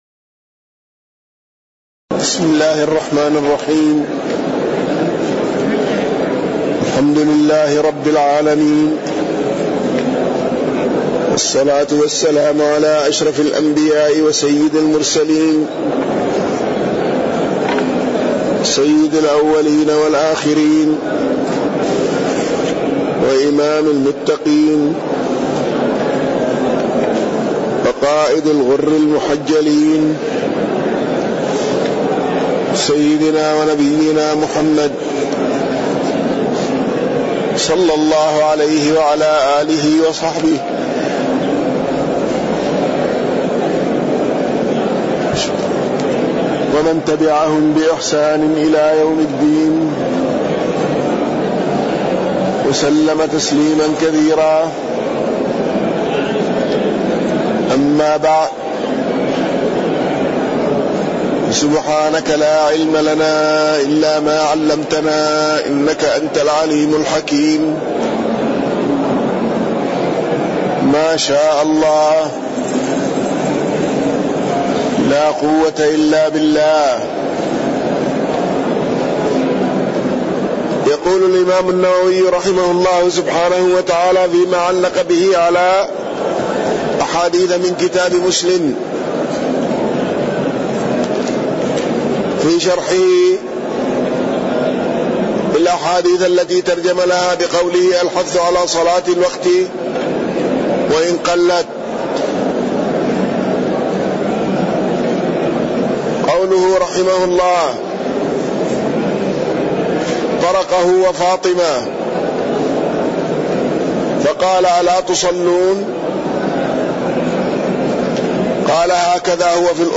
تاريخ النشر ٨ ربيع الأول ١٤٣١ هـ المكان: المسجد النبوي الشيخ